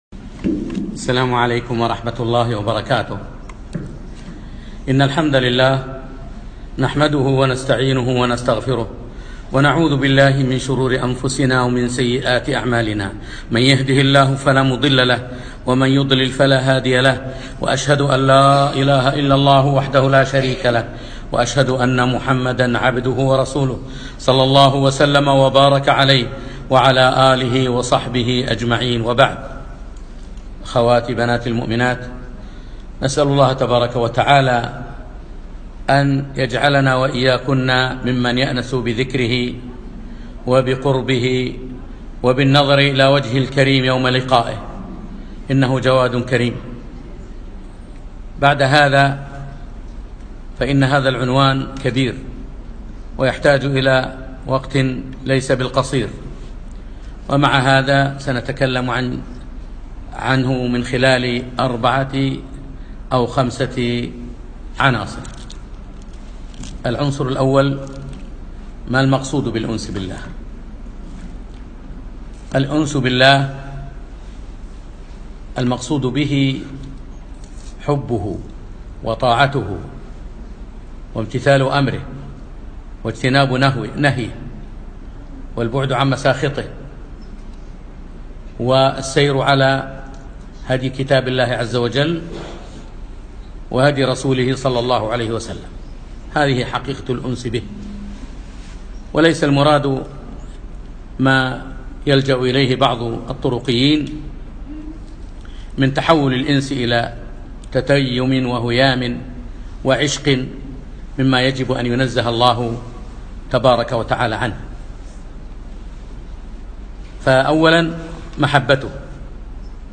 يوم الثلاثاء 7 رجب 1438 الموافق 5 4 2017 في مركز الفردوس لدار القرآن نساء مسائي الفردوس